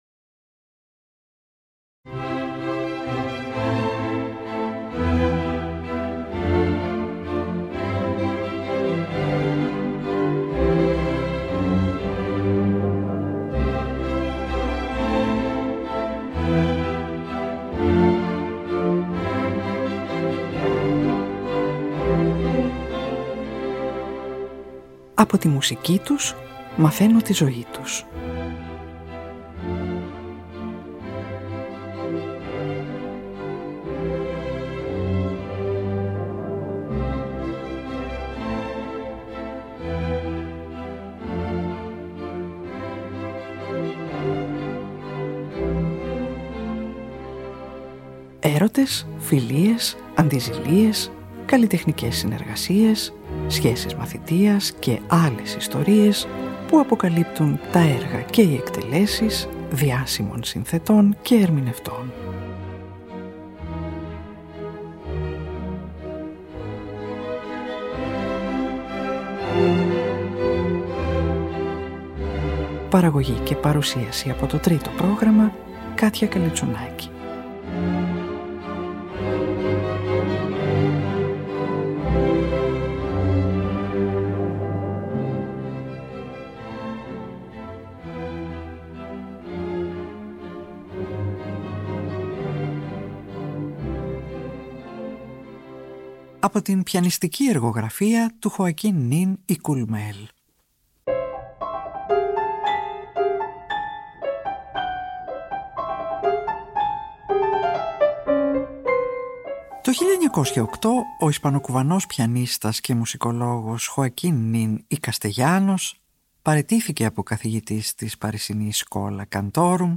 Κοντσέρτο για πιάνο